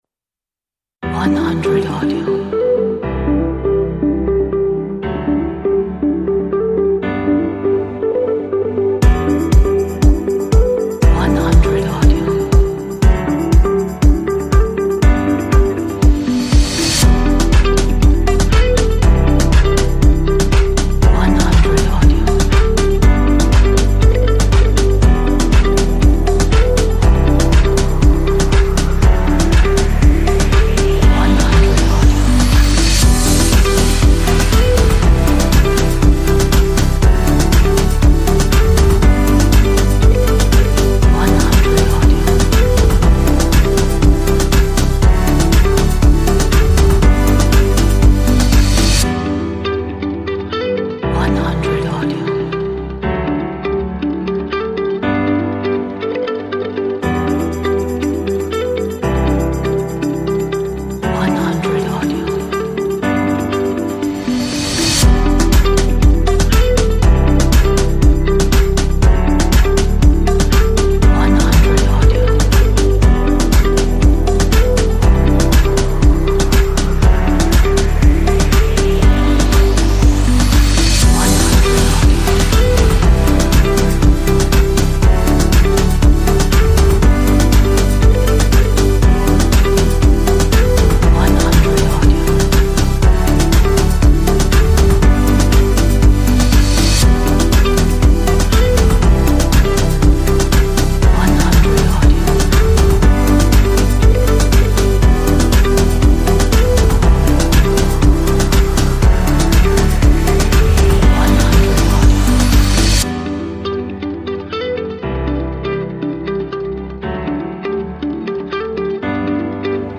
Tech Business Corporate track for your projects.